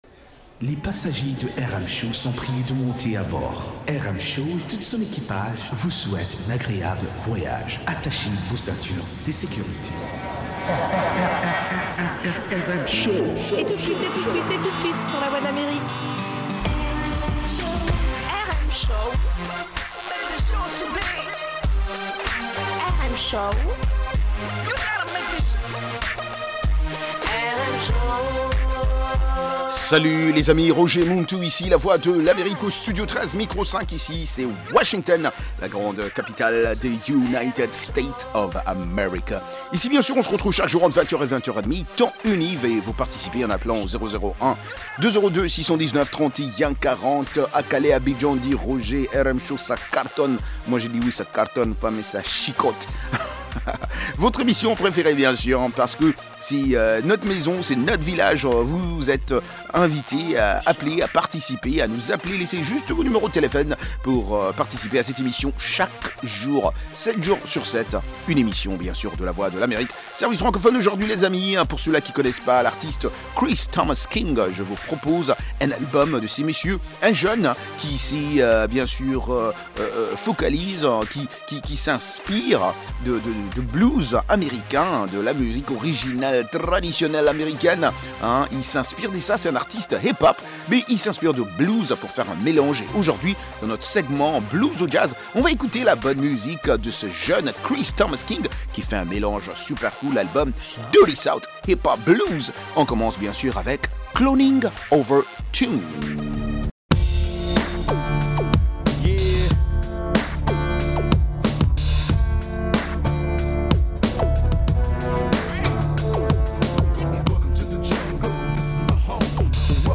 Blues and Jazz Program